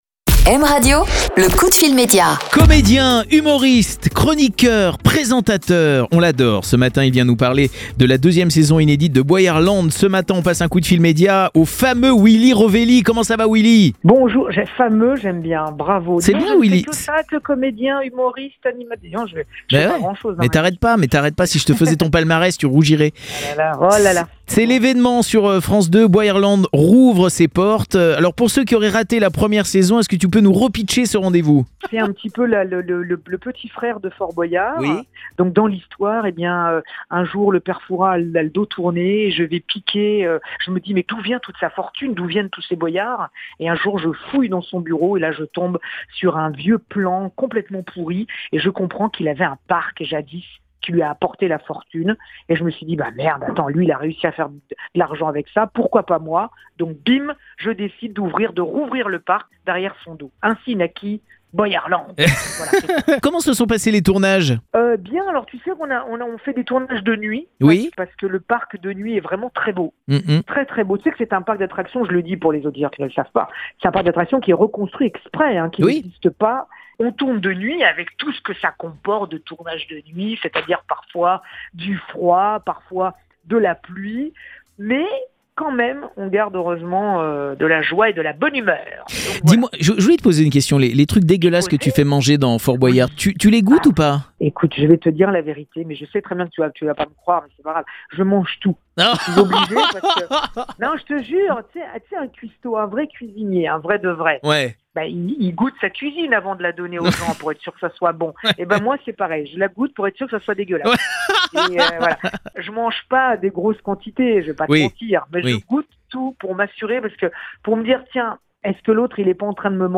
L'invité Média : Willy Rovelli